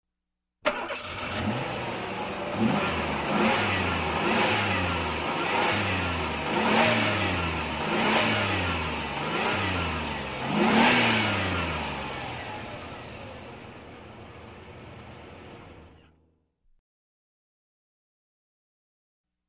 Стартер: двигатель не заводится, 4 раза (loop) Скачать звук music_note Авто , машины , транспорт save_as 196.7 Кб schedule 0:20:00 15 2 Теги: loop sound , mp3 , vehicle , авто , двигатель , зажигание , звук , мотор , стартер , Транспорт